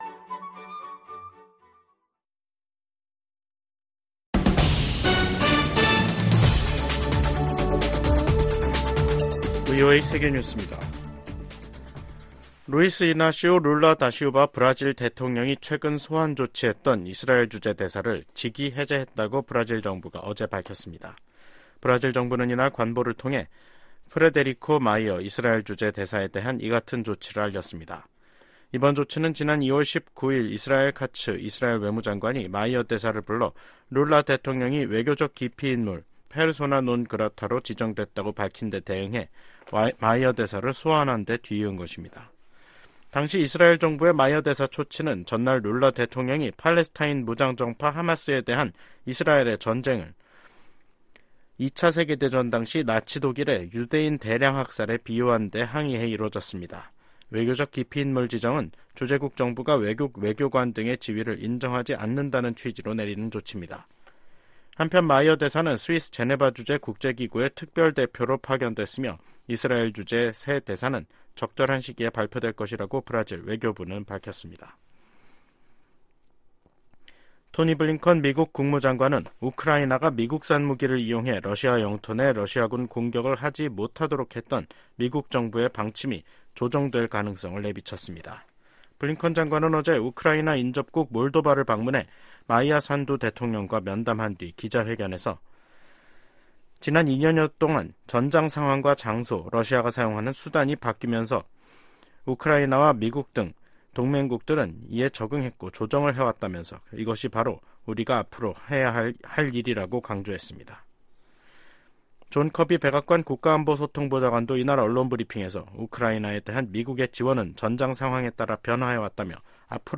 VOA 한국어 간판 뉴스 프로그램 '뉴스 투데이', 2024년 5월 30일 2부 방송입니다. 북한이 30일, 동해상으로 단거리 탄도미사일 10여발을 발사했습니다.